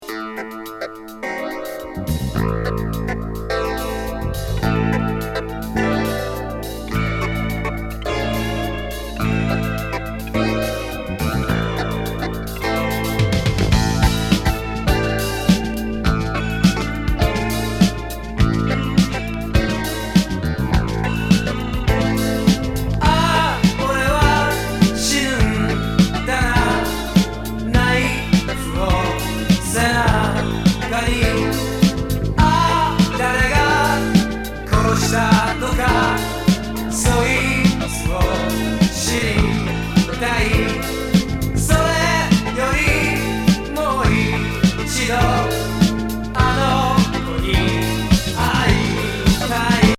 スリリング・ファンク・グルーヴ